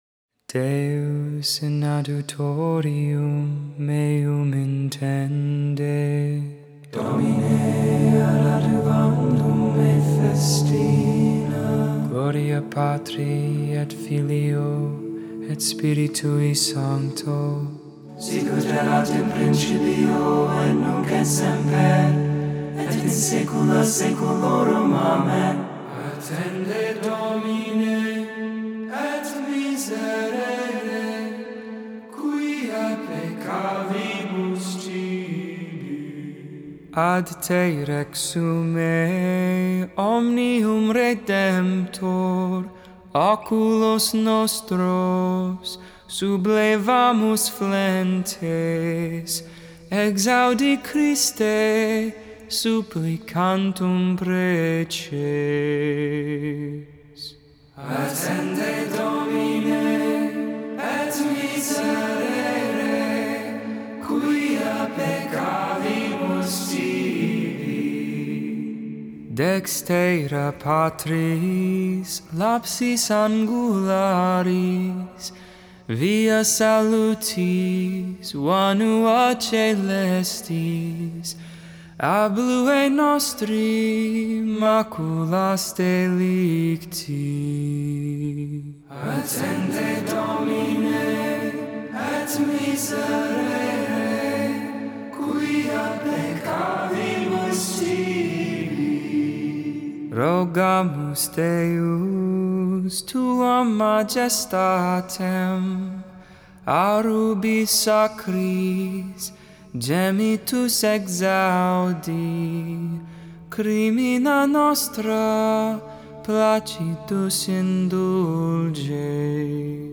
Hymn
Simple Tone 8